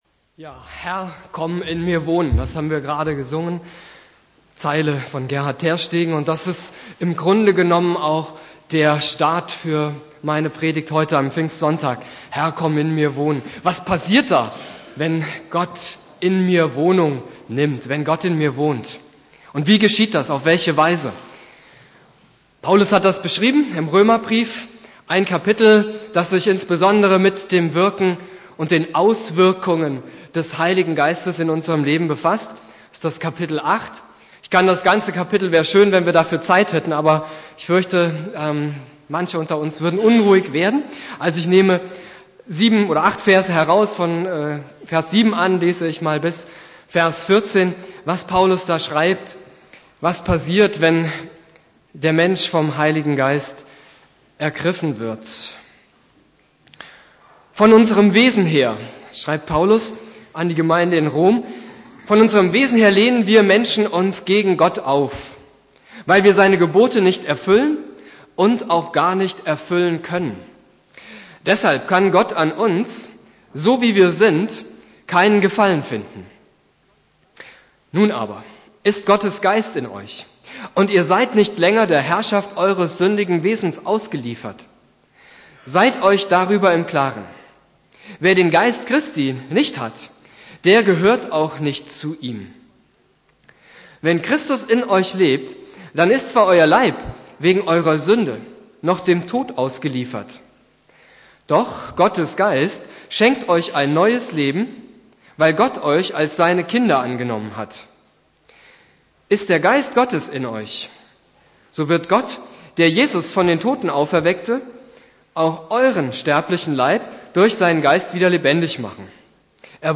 Predigt
Pfingstsonntag Prediger